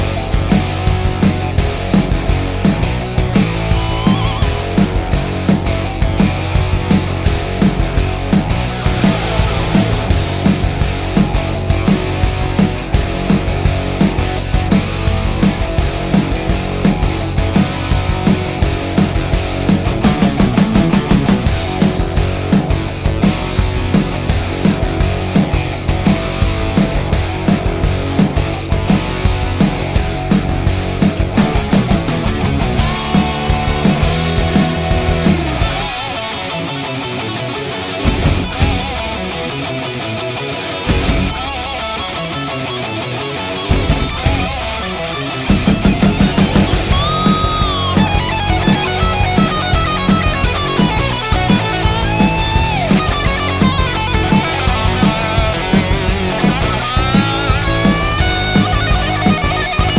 no Backing Vocals Irish 3:09 Buy £1.50